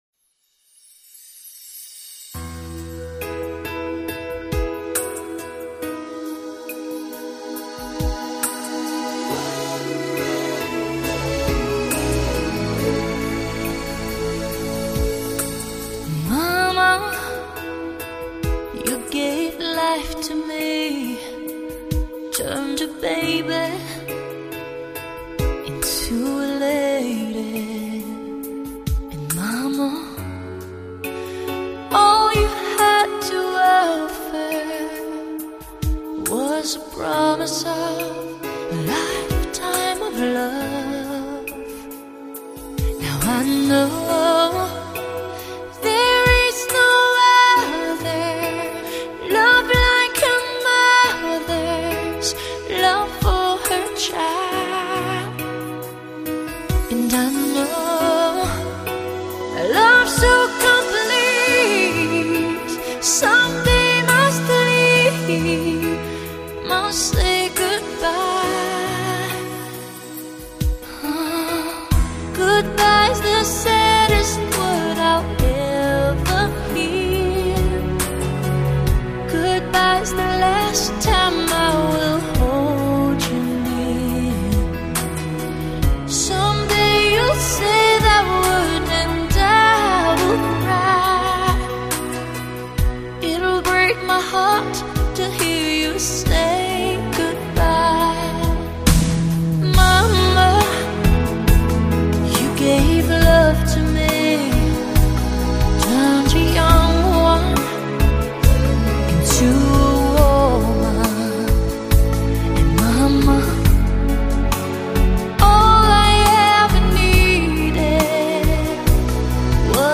令人潸然泪下的